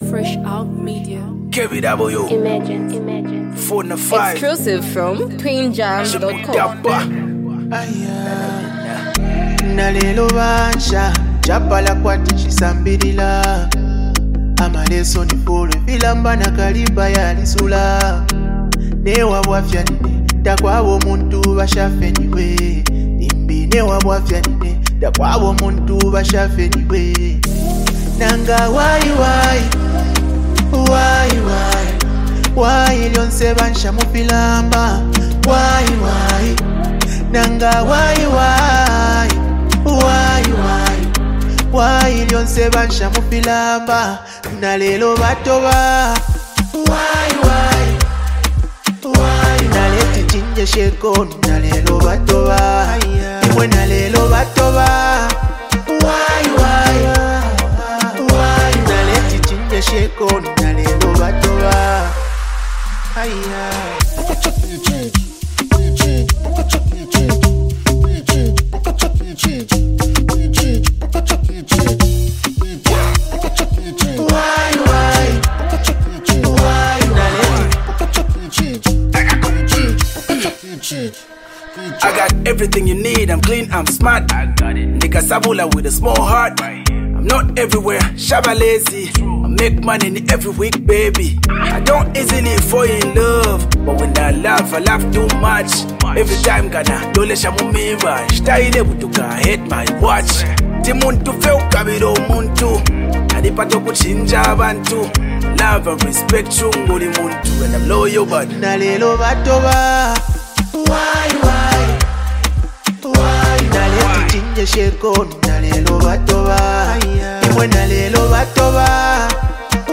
Zambian music
rap duos